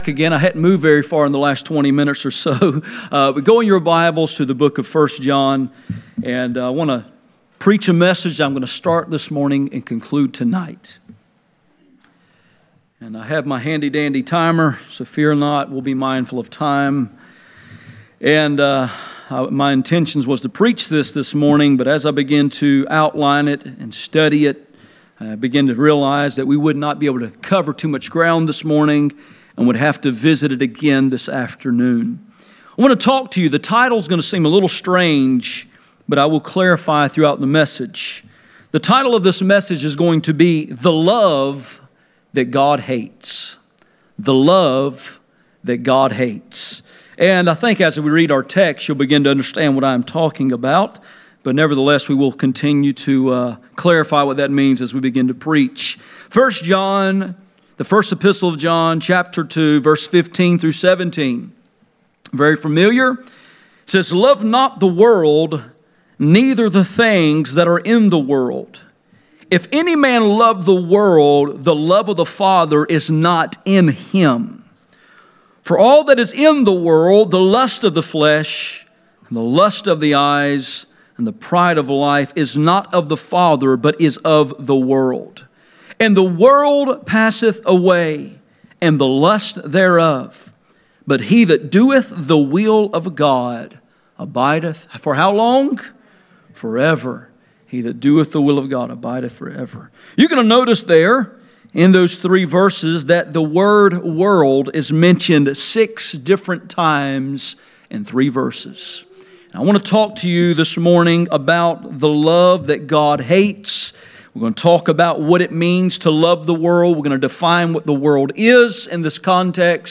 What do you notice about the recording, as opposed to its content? None Passage: 1 John 2:15-17 Service Type: Sunday Morning %todo_render% « The baptism of the Holy Ghost